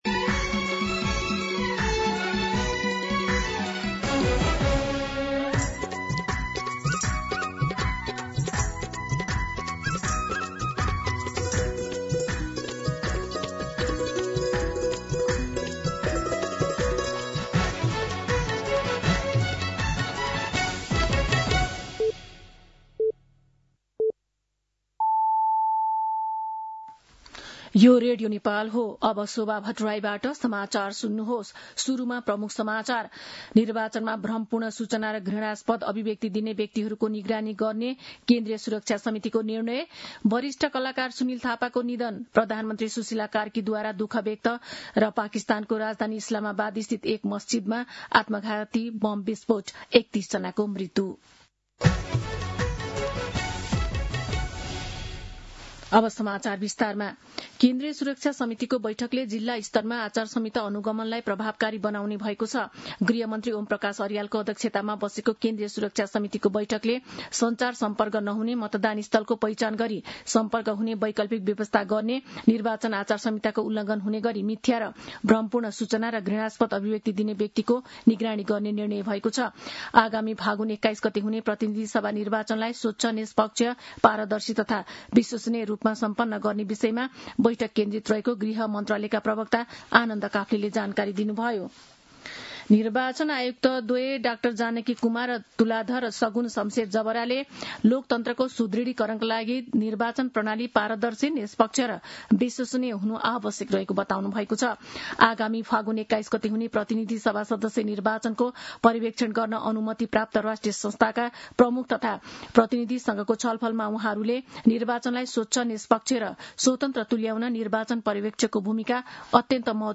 दिउँसो ३ बजेको नेपाली समाचार : २४ माघ , २०८२
3-pm-Nepali-News-1.mp3